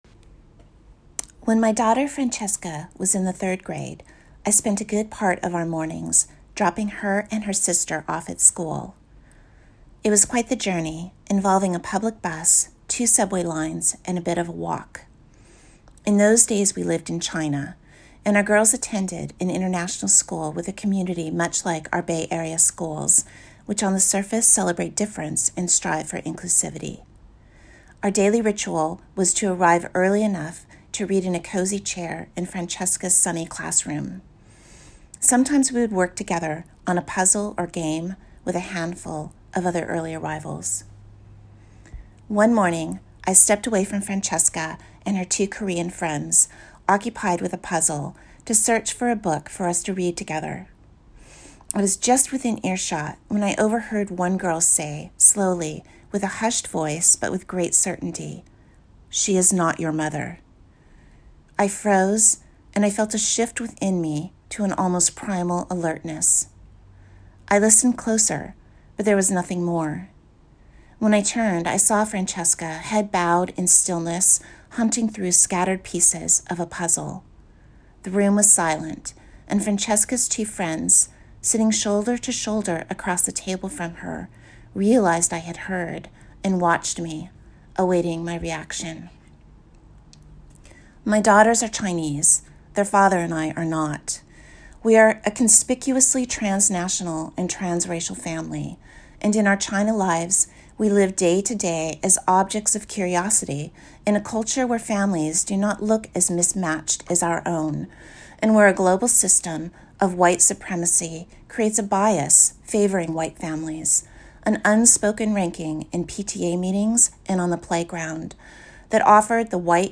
Good Friday Reflections